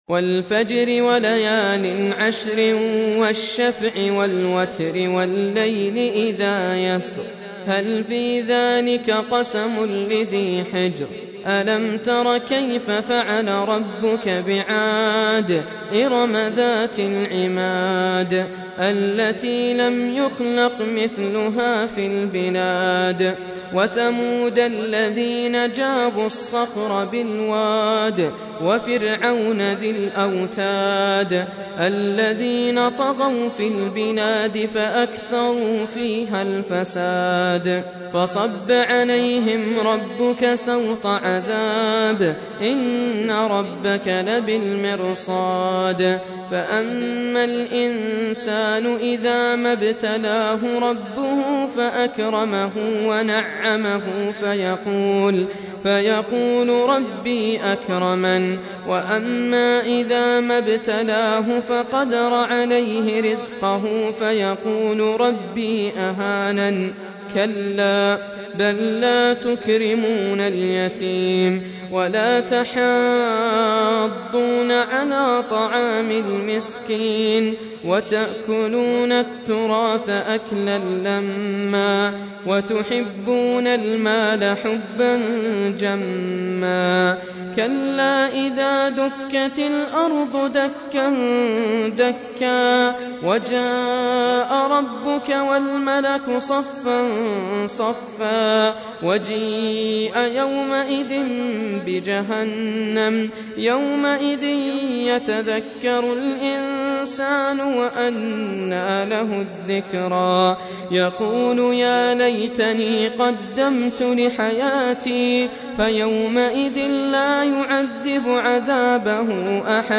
Surah Sequence تتابع السورة Download Surah حمّل السورة Reciting Murattalah Audio for 89. Surah Al-Fajr سورة الفجر N.B *Surah Includes Al-Basmalah Reciters Sequents تتابع التلاوات Reciters Repeats تكرار التلاوات